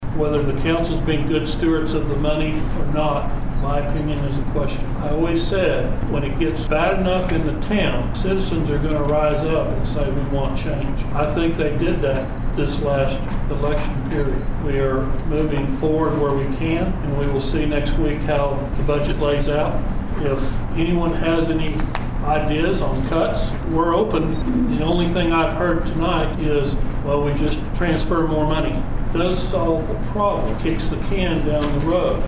A public hearing was held on Tuesday evening at Pawhuska's community center regarding the budget for the 2025-2026 fiscal year.